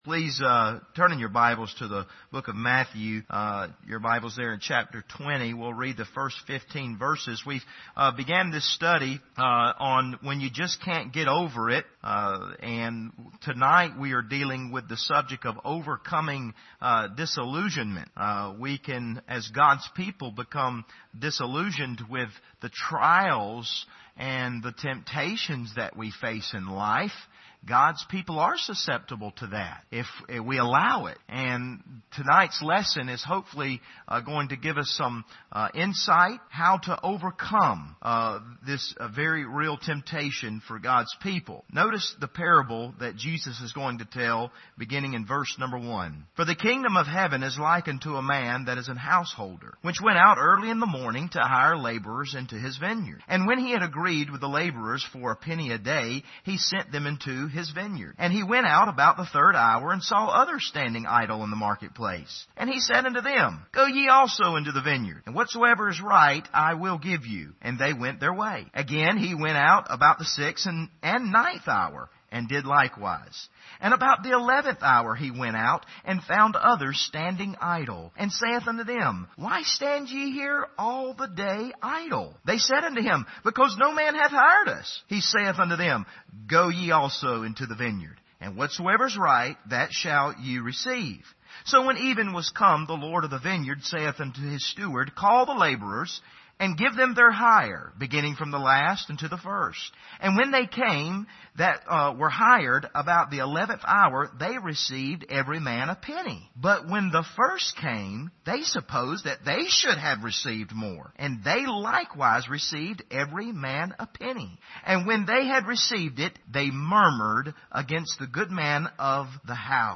Passage: Matthew 20:1-15 Service Type: Wednesday Evening